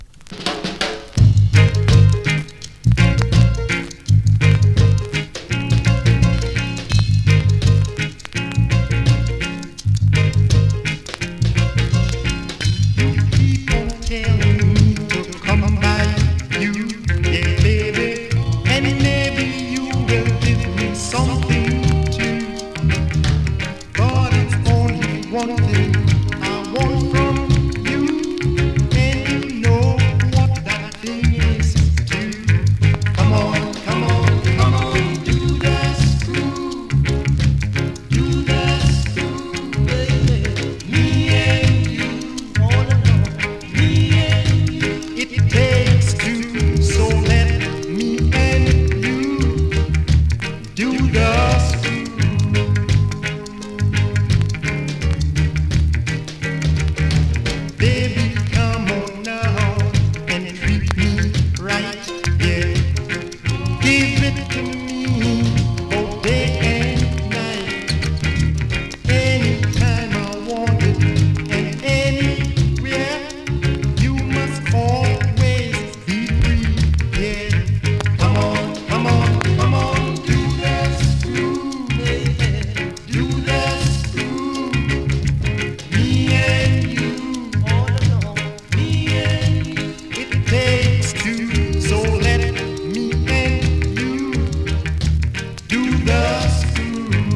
コメントMEGA RARE ROCKSTEADY!!
スリキズ、ノイズそこそこありますが